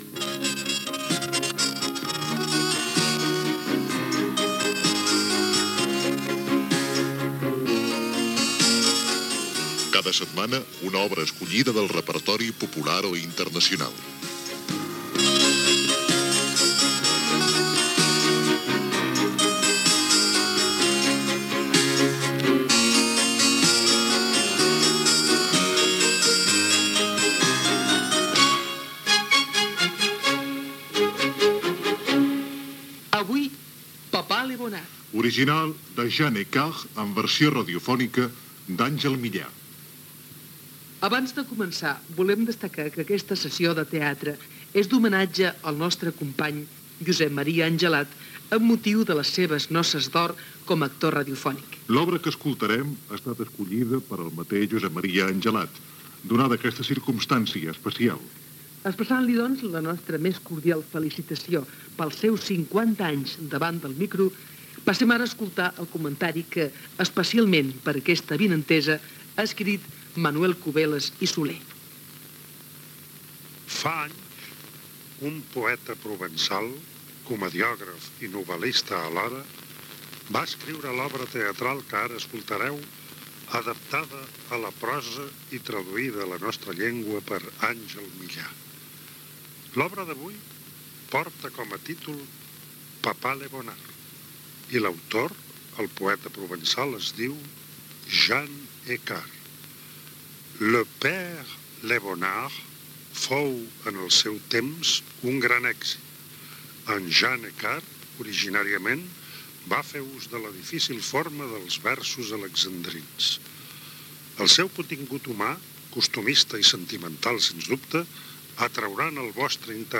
Conversa entre el pare i la seva filla. Arriba el criat que parla amb el seu senyor. Després el matrimoni dialoga sobre la seva filla. Gènere radiofònic Ficció